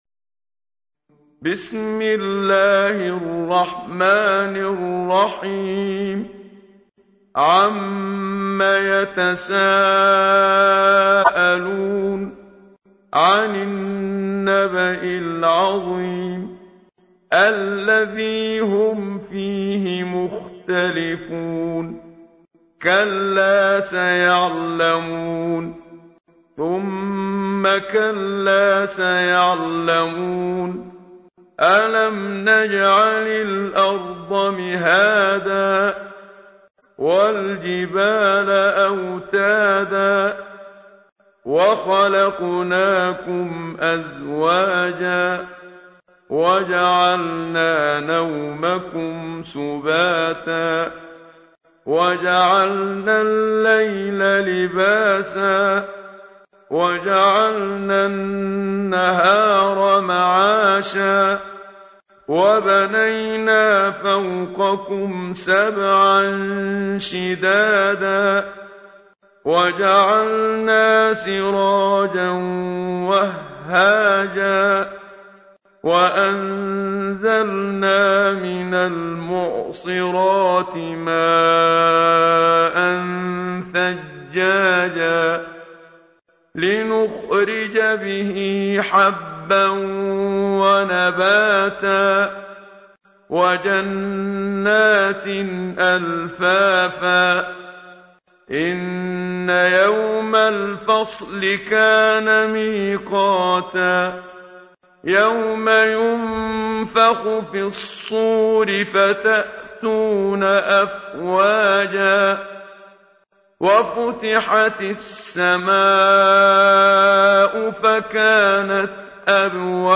تحميل : 78. سورة النبأ / القارئ محمد صديق المنشاوي / القرآن الكريم / موقع يا حسين